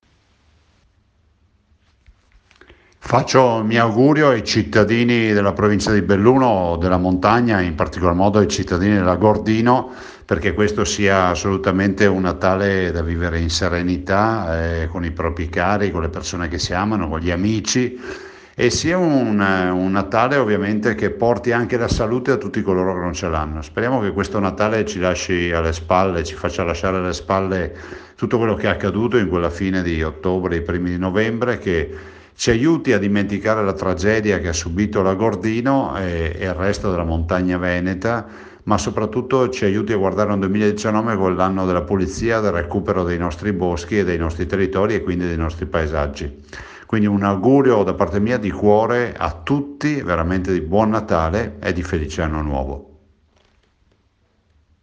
DAL GOVERNATORE REGIONALE IL MESSAGGIO DI AUGURI AUDIO